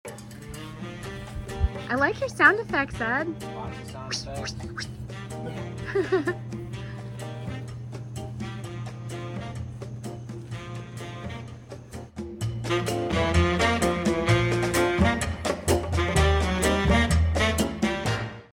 Hedge trimming made possible by sound effects free download